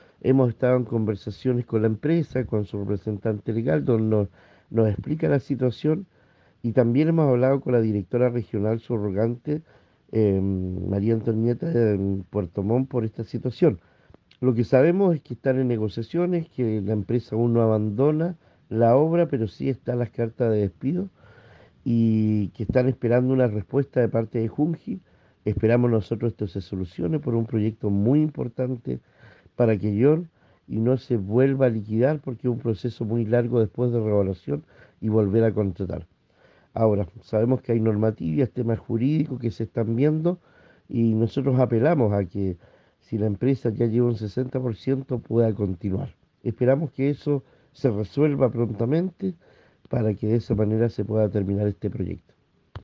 Junto con ello, el edil acotó que han existido conversaciones con la empresa y su representante legal, donde se explica los hechos.